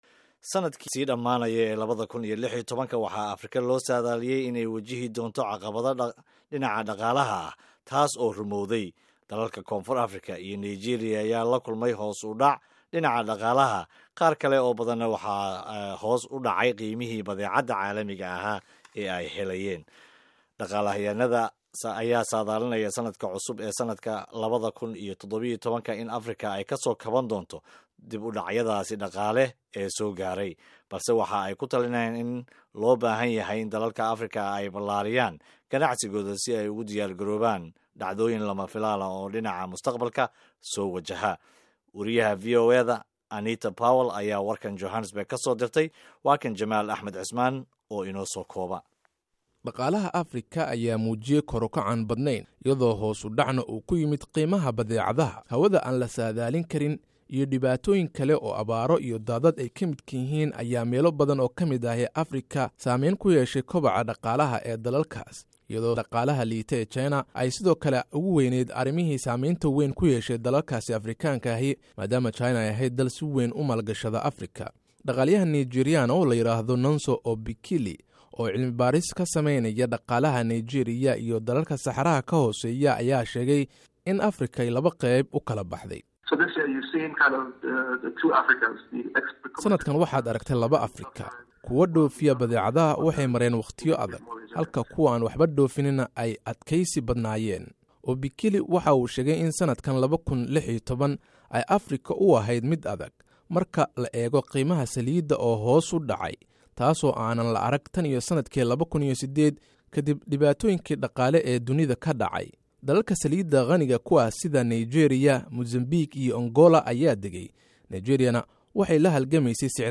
Dhageyso Warbixintaa Dhaqaalah Afrika